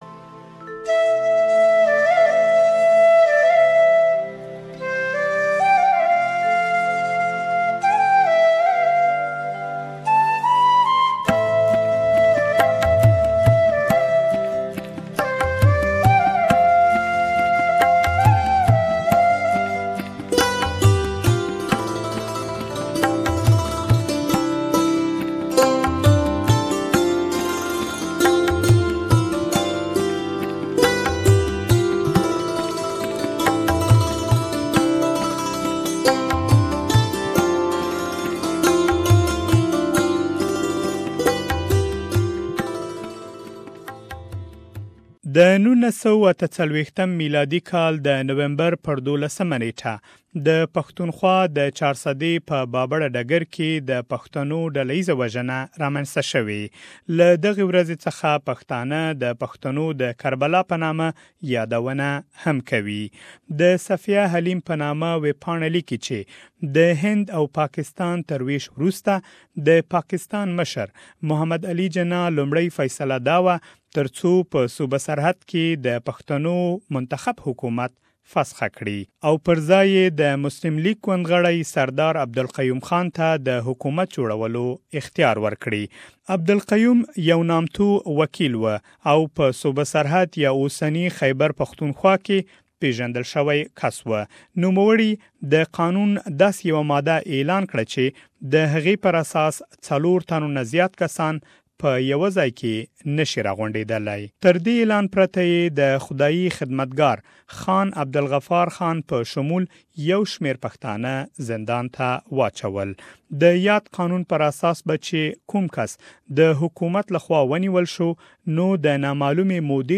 For more details, please listen to the full report in Pashto.